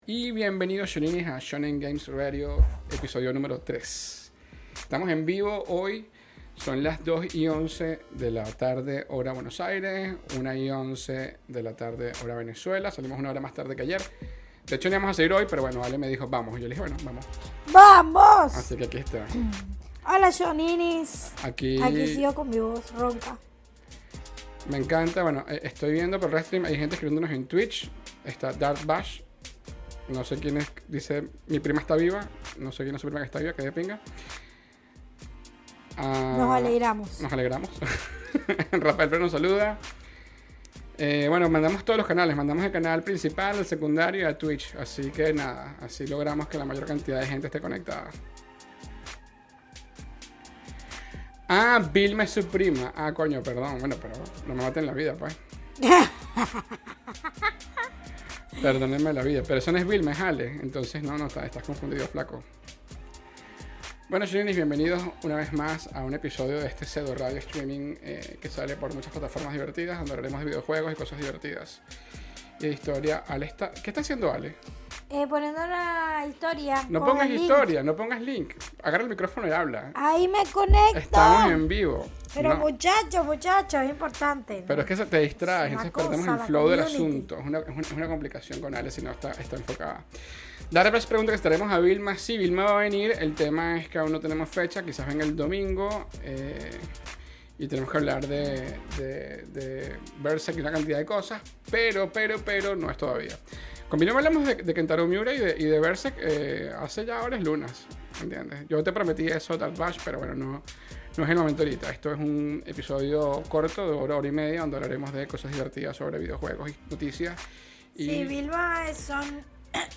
Cuando eres papá de muchas hijas y tratas de hacer un programa en vivo, muchas cosas no planificadas pasan en el medio